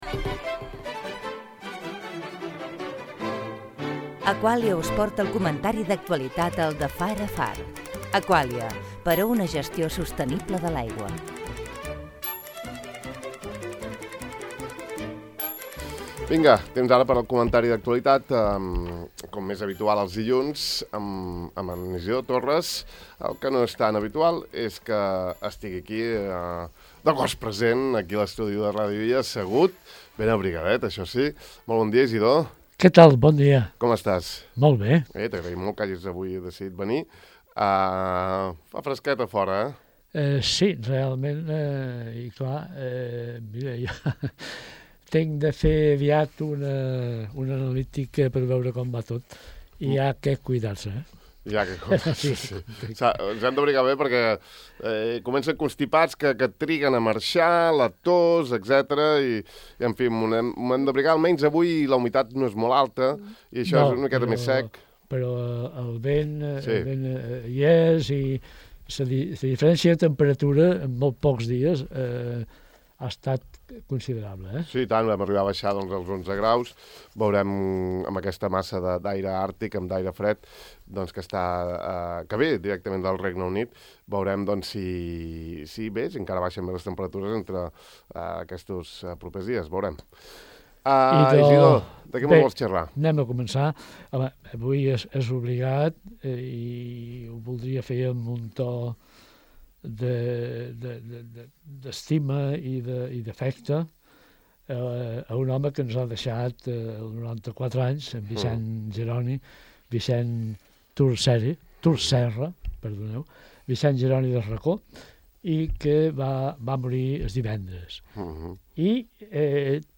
Emocionat, l’ha recordat i l’hem escoltat en una gravació.